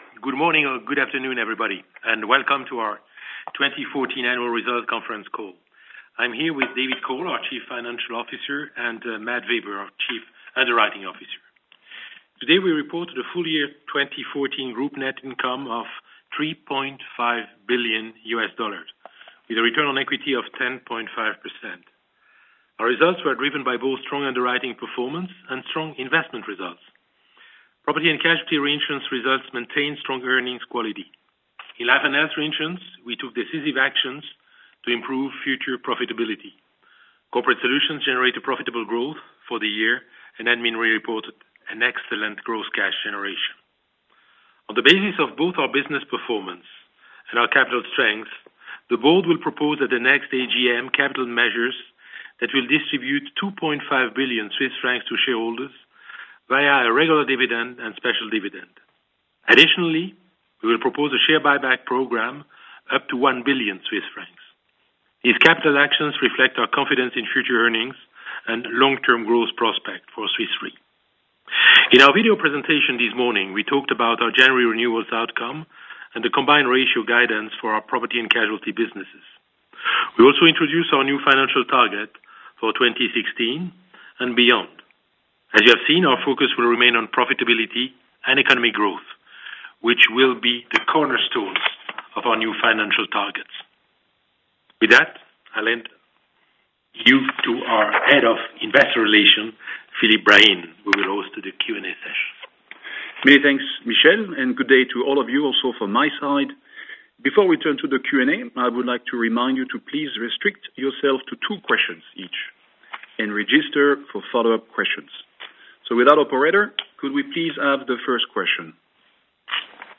Analysts Conference call recording
2014_fy_investors_qa.mp3